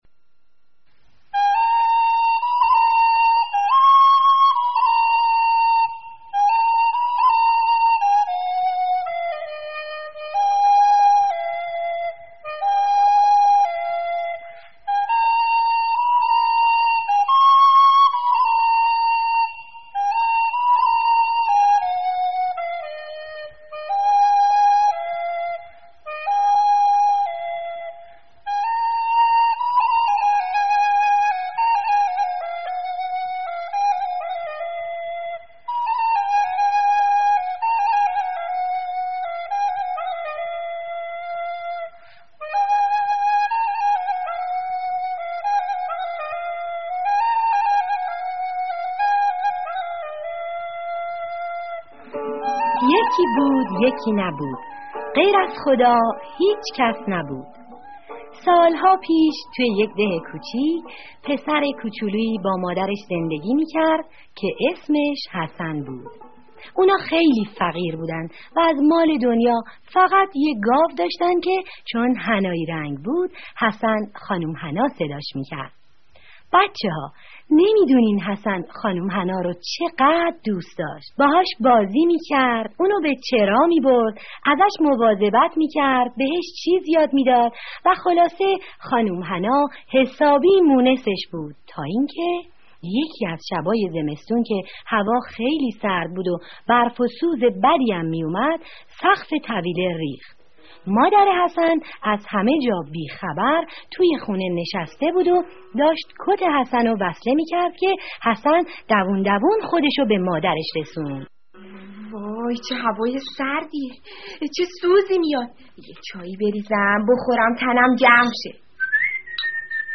قصه صوتی کودکانه: «حسن و خانم حنا» در «لوبیای سحرآمیز» برای کودکان و خردسالان – کامل